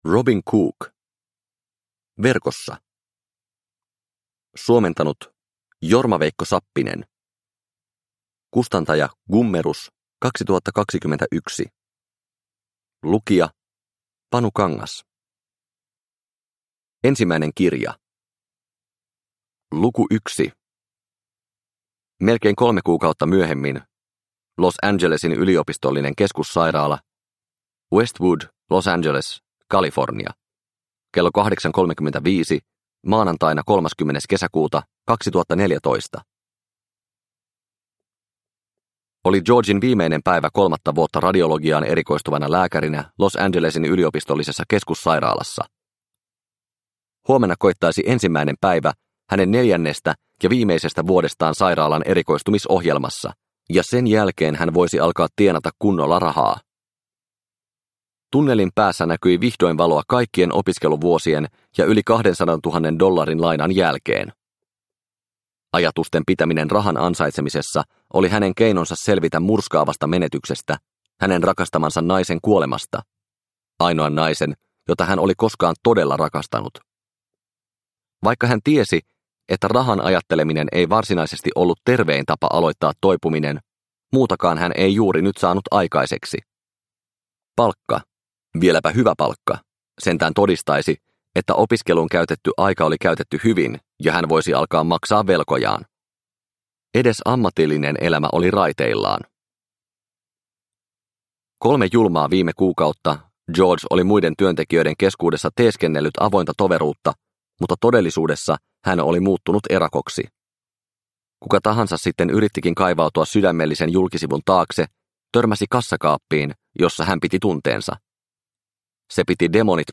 Verkossa – Ljudbok – Laddas ner